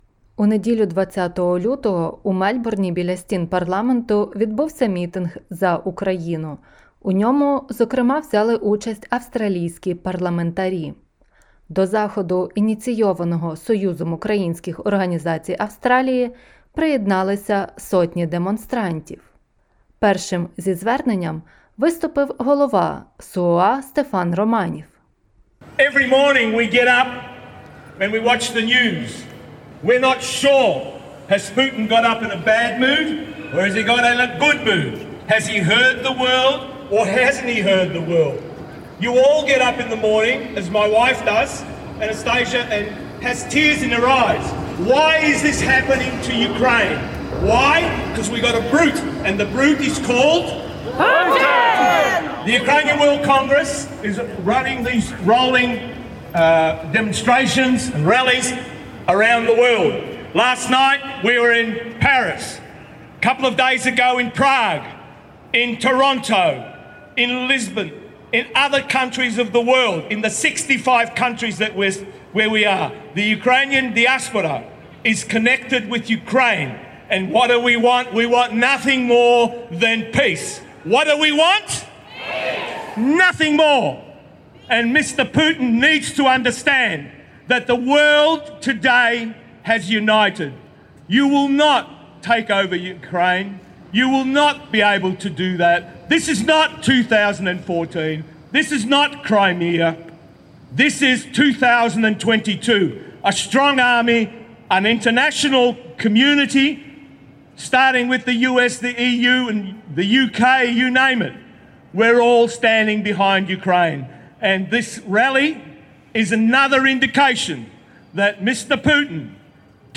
У неділю, 20 лютого, у Мельбурні біля стін Парламенту відбувся мітинг за Україну. У ньому зокрема взяли участь австралійські парламентарі.